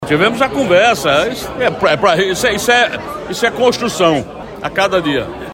Durante entrevista ao programa Correio Debate, da Rádio Correio 98 FM, desta quinta-feira (10/08), o governador mediu como construção o diálogo entre eles.